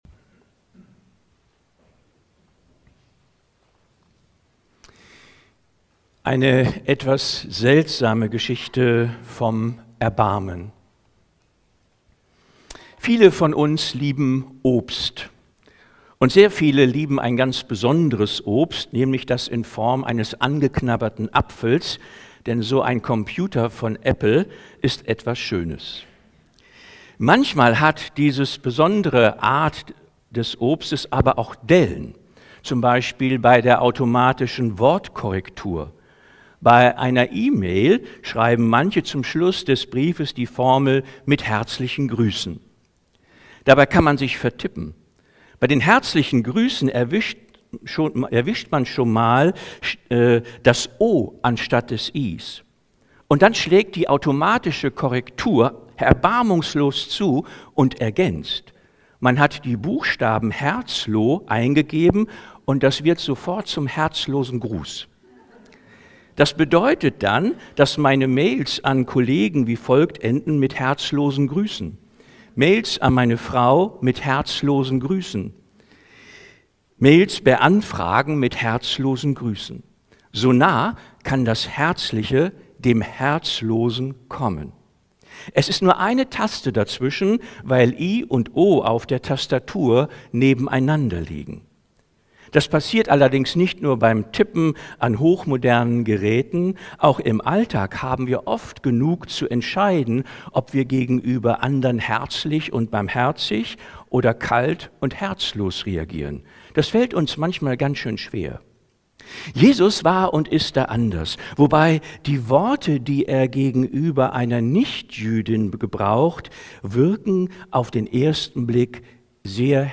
Predigten aus der Baptistengemeinde Leer